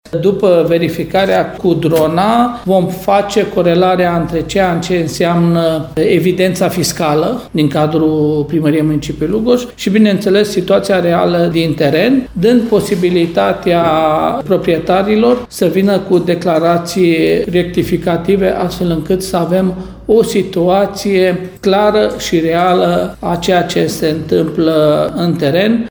Mai exact, municipalitatea urmărește să reglementeze structurile ridicate fără autorizație, spune Călin Dobra, primarul municipiului Lugoj.